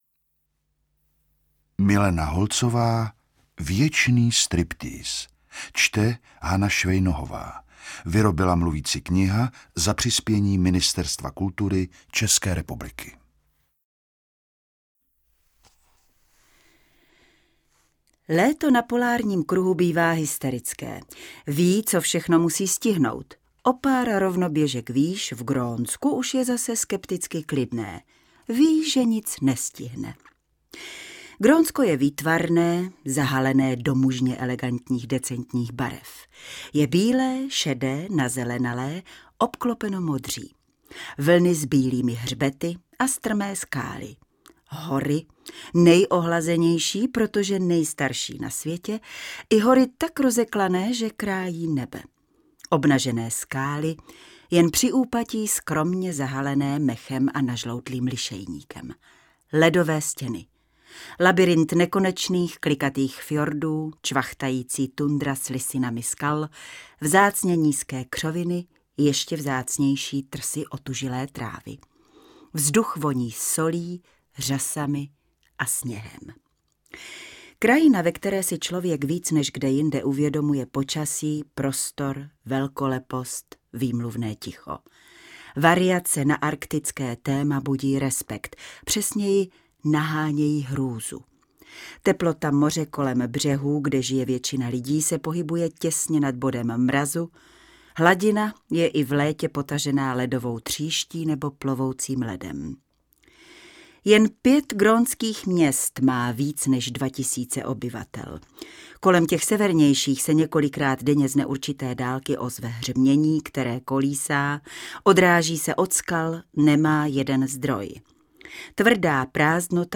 Čte: